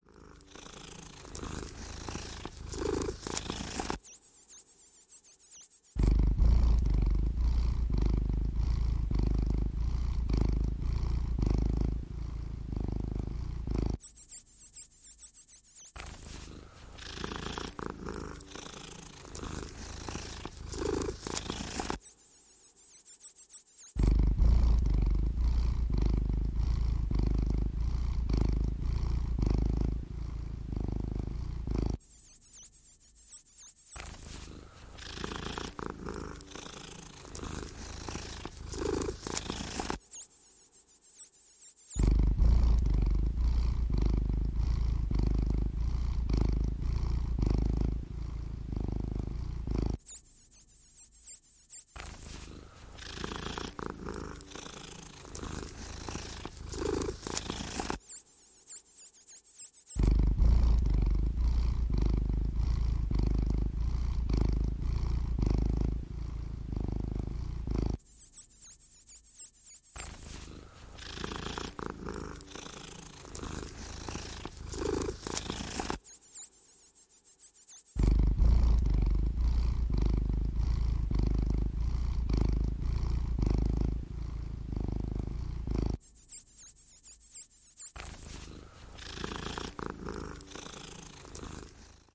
Звук, чтобы кот подошёл